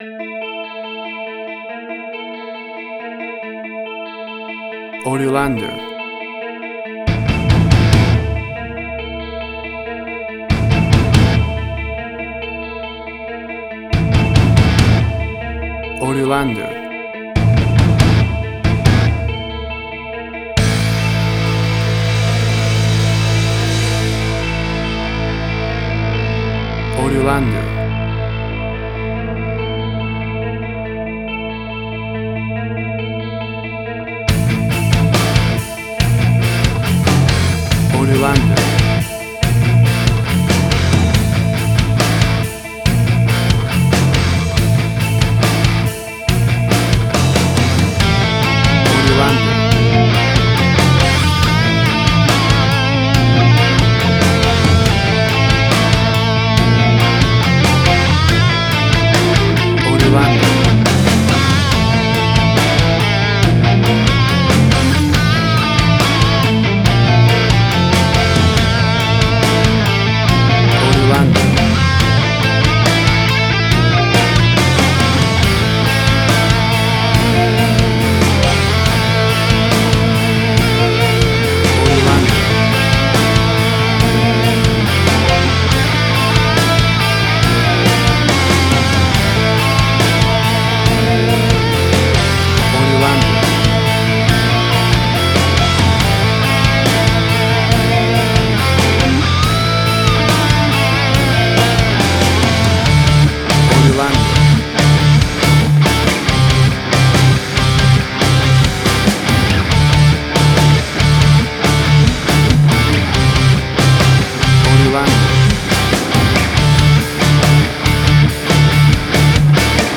Hard Rock, Similar Black Sabbath, AC-DC, Heavy Metal
Tempo (BPM): 70